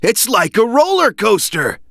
From The Cutting Room Floor Jump to navigation Jump to search File File history File usage Metadata All01_e03_kn.ogg  (Ogg Vorbis sound file, length 1.9 s, 262 kbps) This file is an audio rip from a(n) Xbox 360 game. This file is an audio rip from a(n) PlayStation 3 game.